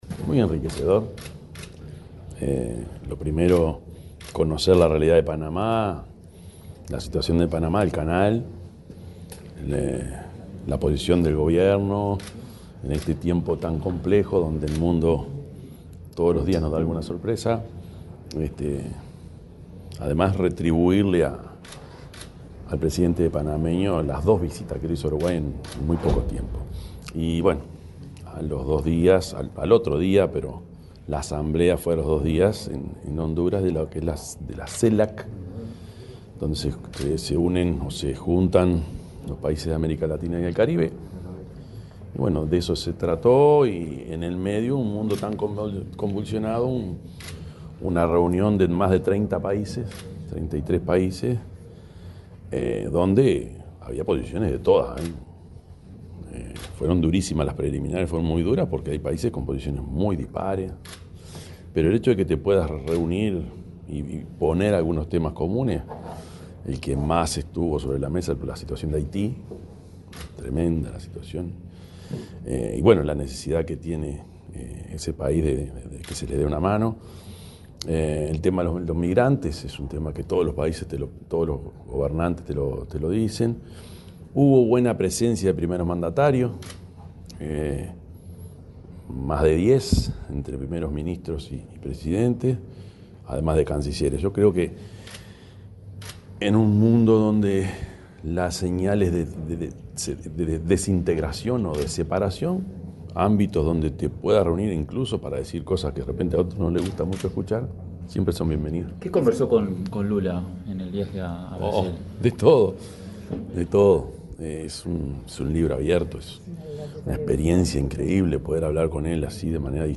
Declaraciones del presidente de la República, Yamandú Orsi
El presidente de la República, Yamandú Orsi, dialogó con la prensa en la base aérea, luego de su arribo de Panamá y Honduras, donde, entre otras